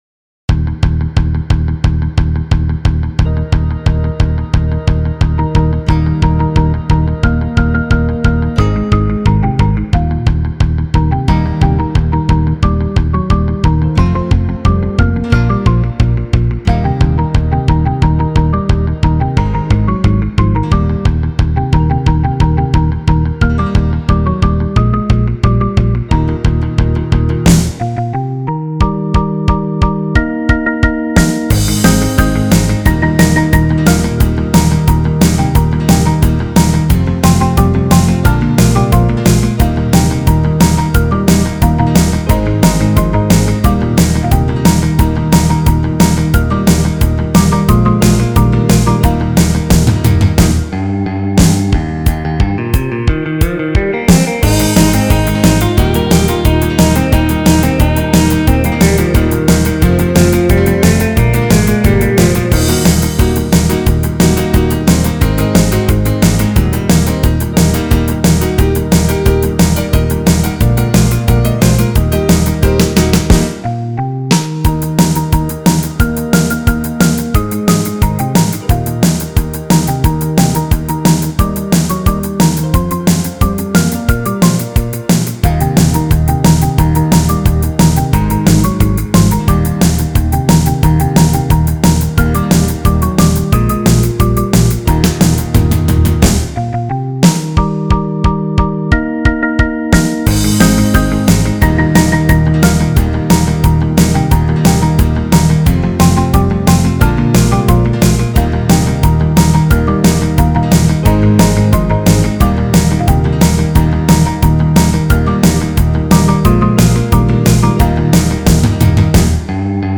the constant muted guitar on track 6